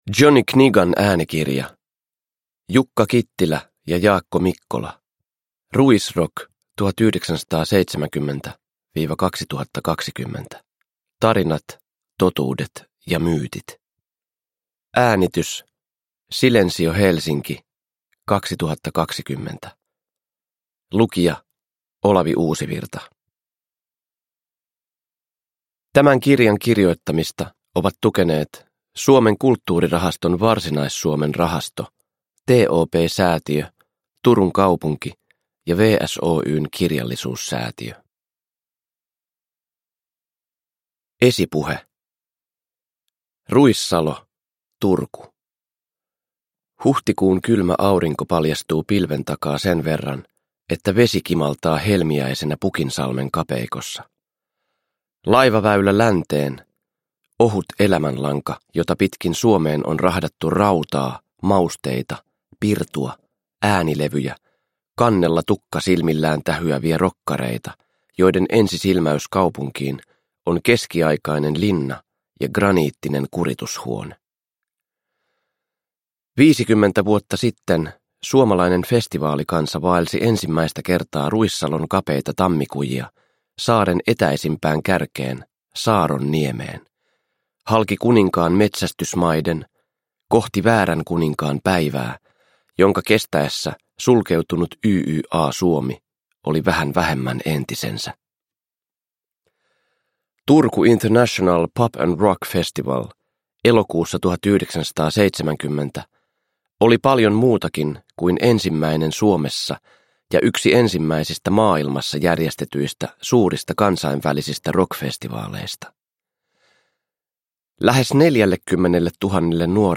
Ruisrock 1970-2020 – Ljudbok – Laddas ner
Uppläsare: Olavi Uusivirta